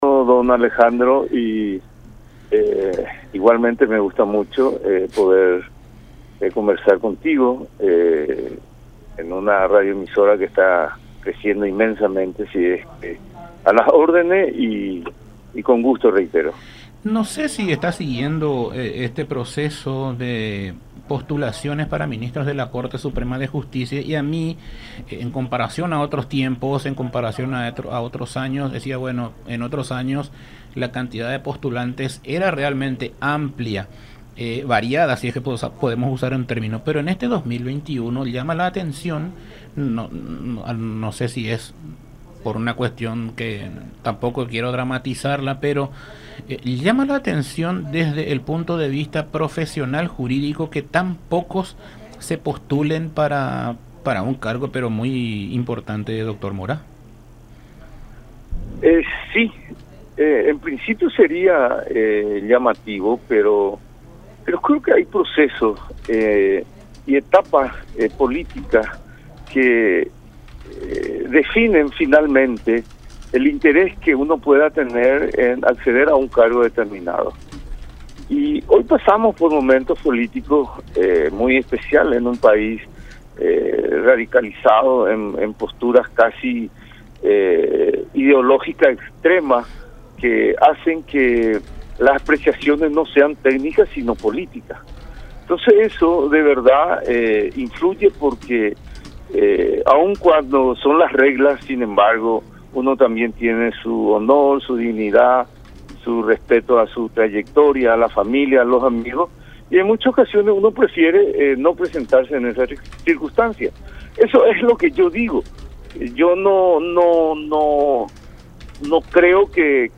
en diálogo con Todas Las Voces a través La Unión